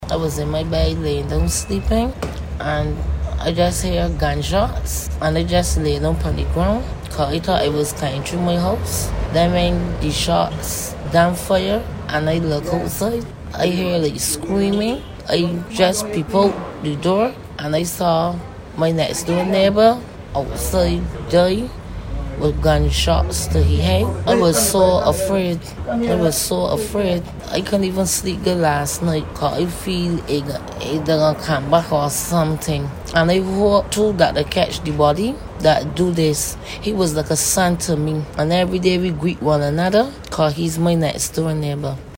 When Starcom Network News visited the area, A resident said the ordeal has left her scared.